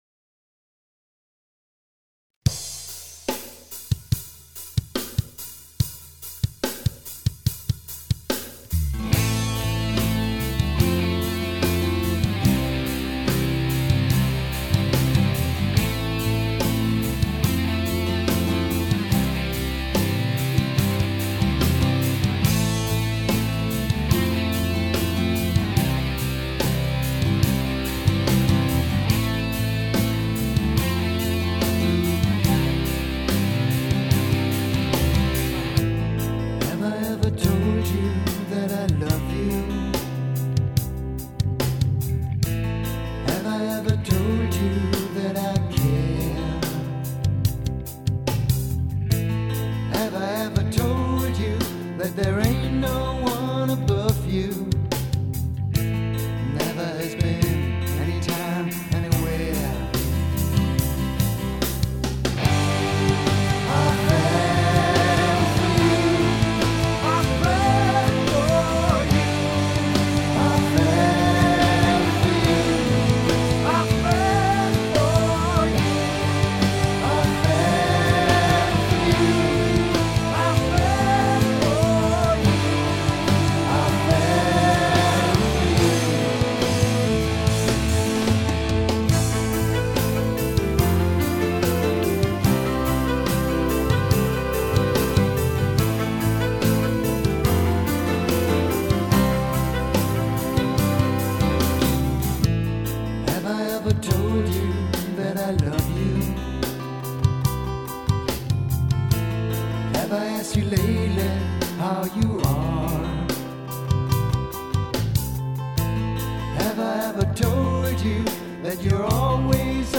Recorded at MotherMoon Schopfheim and Mellsonic Steinen
Guitars, String-Piano-Arrangement
Guitar, Vocals